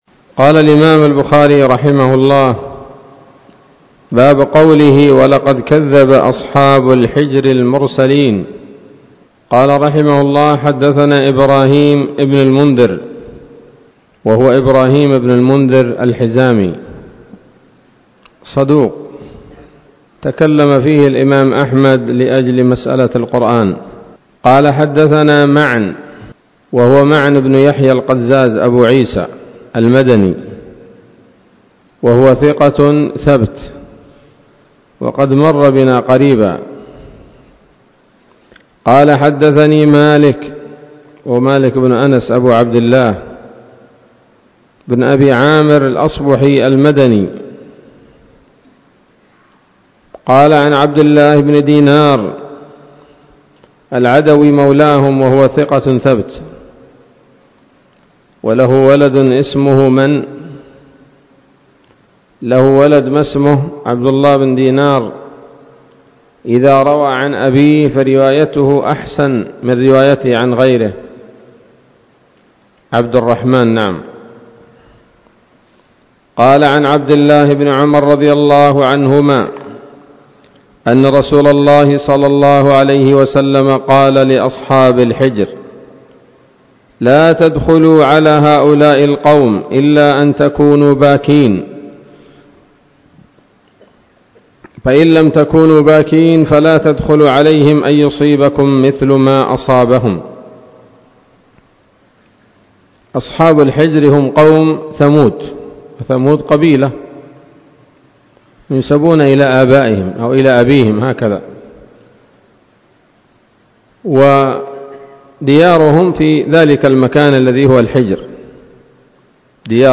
الدرس السابع والأربعون بعد المائة من كتاب التفسير من صحيح الإمام البخاري